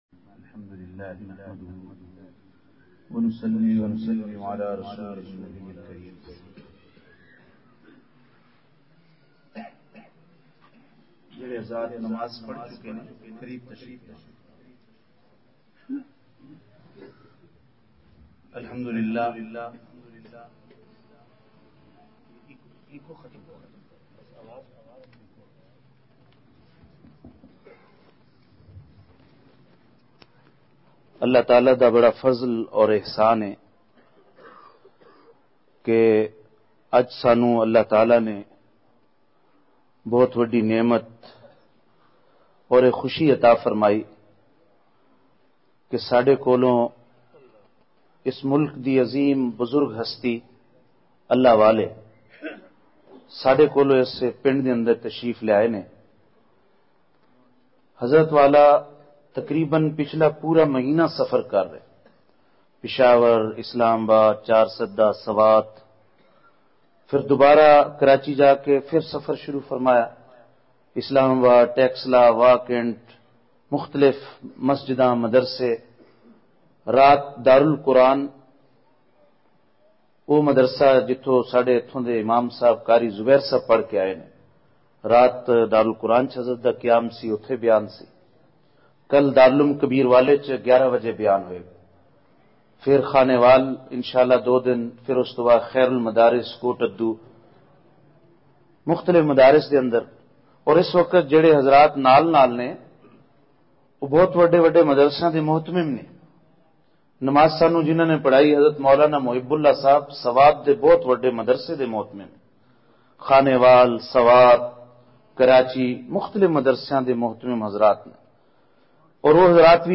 *بمقام:جامع مسجد فاوقیہ چک نمبر ۲۱،۸ آر نزد تلمبہ میاں چنوں*
*نمبر(18):بیان*
بعد مغرب بیان کا آغاز ہوا۔۔